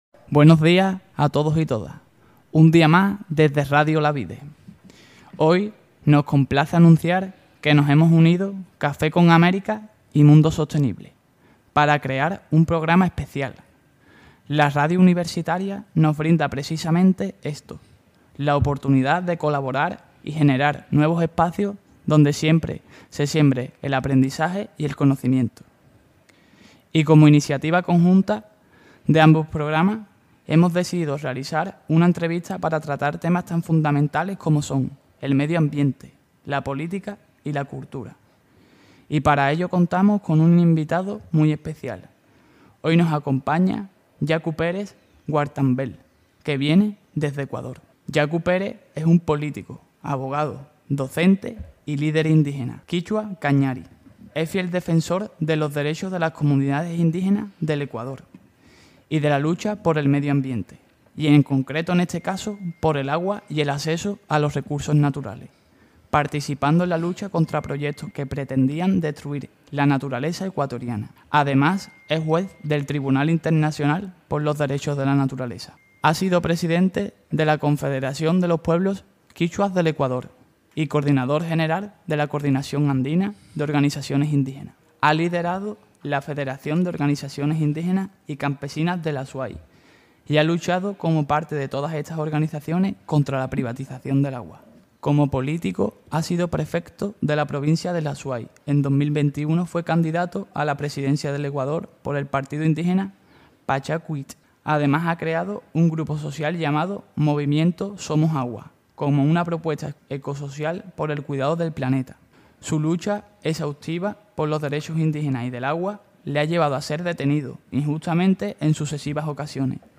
Diciembre - Café con América «Entrevista a Yaku Pérez»